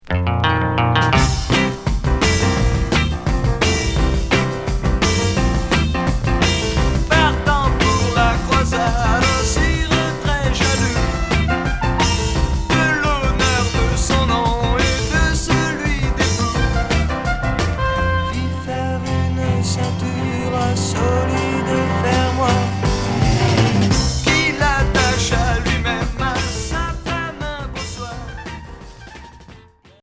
Chanteur 60's Unique EP retour à l'accueil